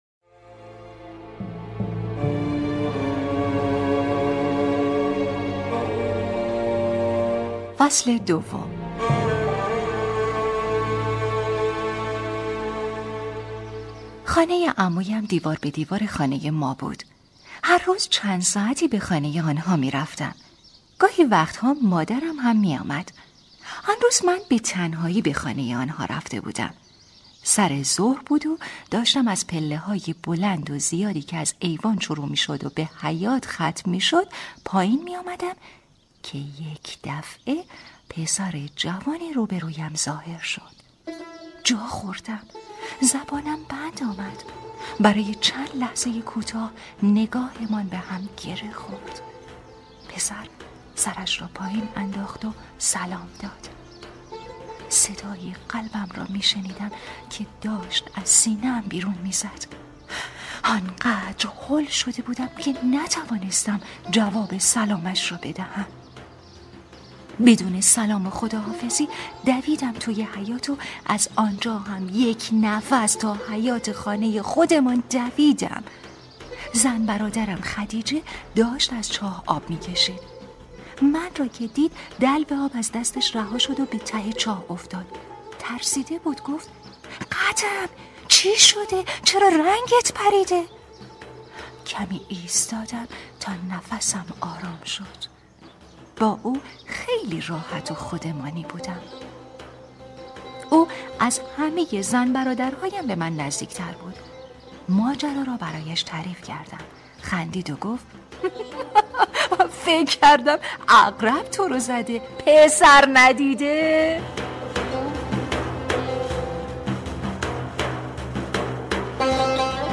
کتاب صوتی | دختر شینا (02)